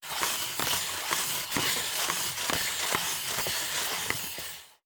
Skates and Ice Skating.wav